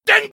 ding_02